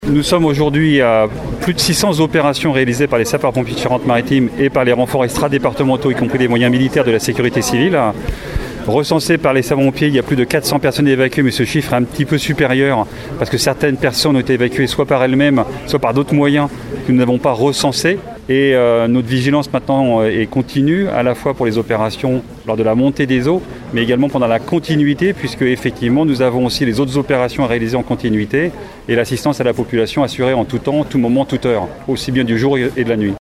A Saintes, les dégâts sont importants comme l’a constaté la secrétaire d’Etat en charge de la biodiversité Bérangère Abba qui s’est rendue sur place hier afin de rencontrer les riverains et les commerçants impactés par les inondations. Elle a tenu à saluer le travail des forces d’intervention et des élus locaux, tout en témoignant de la solidarité de l’Etat aux sinistrés.